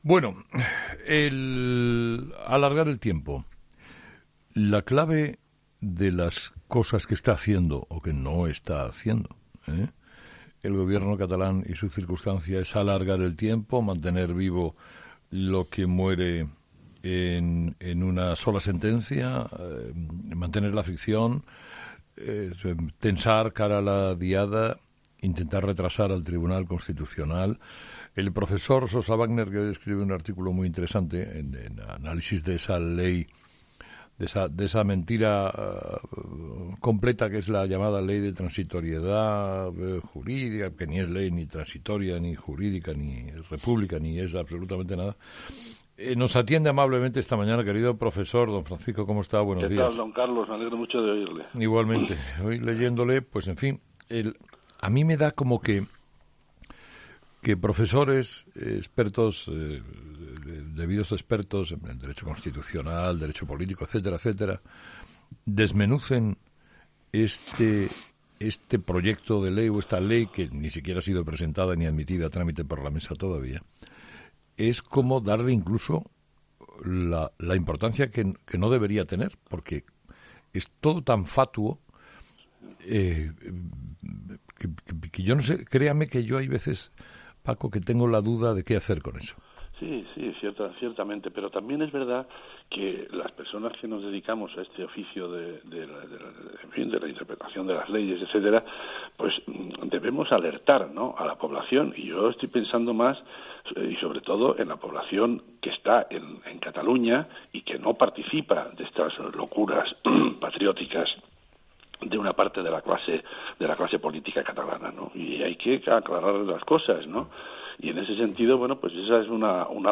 Escucha la entrevista al catedrático Francisco Sosa Wagner en 'Herrera en COPE'